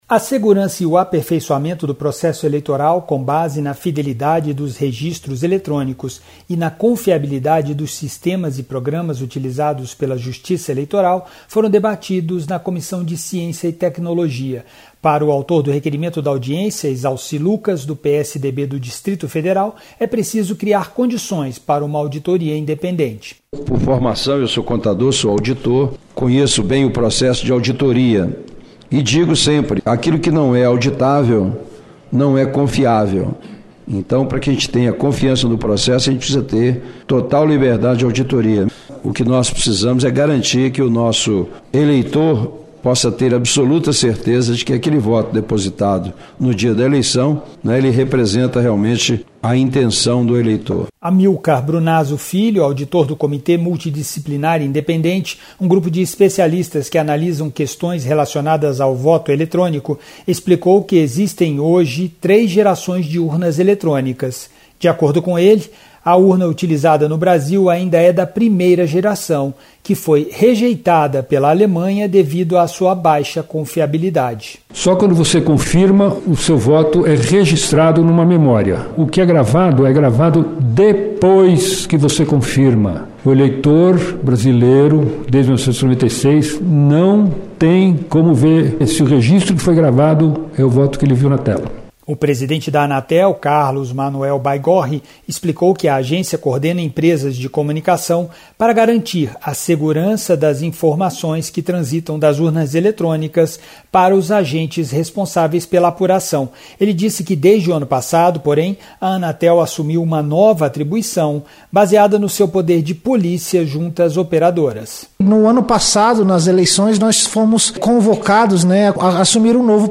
Audiência pública